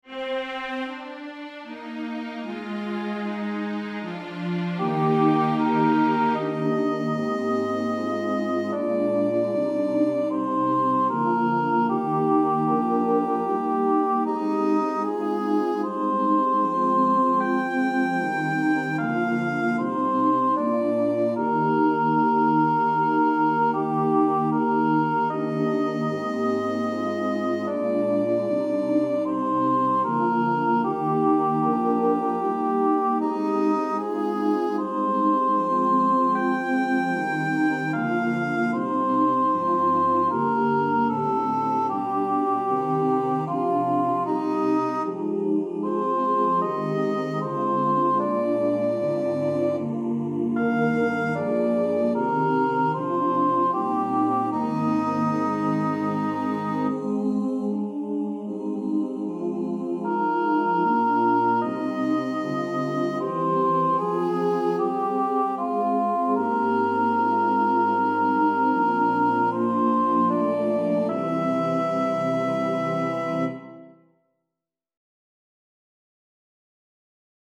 Version with descant